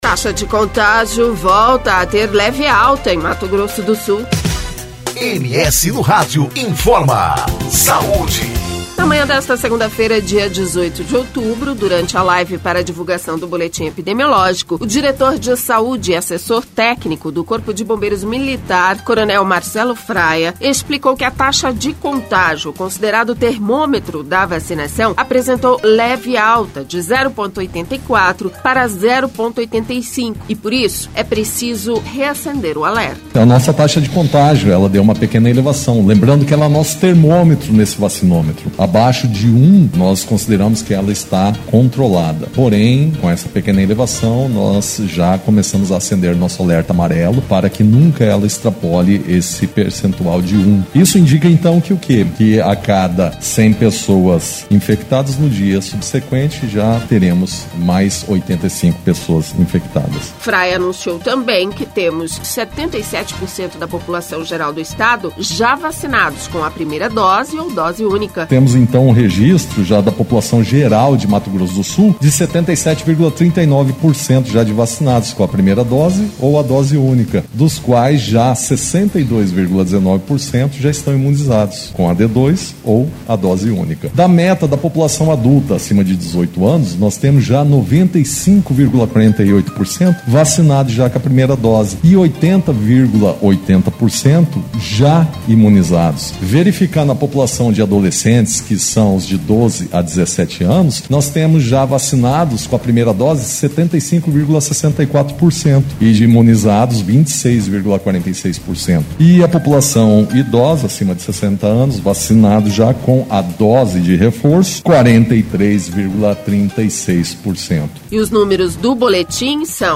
Na manhã desta segunda-feira, dia 18, durante a live para divulgação do boletim epidemiológico, o diretor de Saúde e Assessor Técnico do Corpo de Bombeiros Militar na SES, coronel Marcello Fraiha explicou que a taxa de contágio, considerada o termômetro da vacinação, apresentou leve alta, de 0,84 para 0,85, e por isso é preciso reacender o alerta.